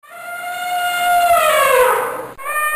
Elefante 1